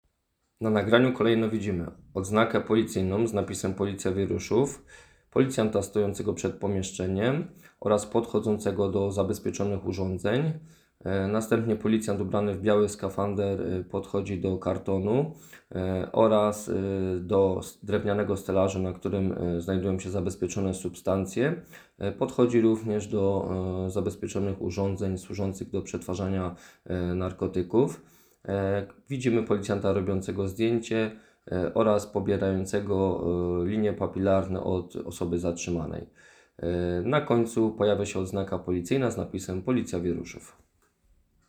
Nagranie audio Audideskrypcja_18.m4a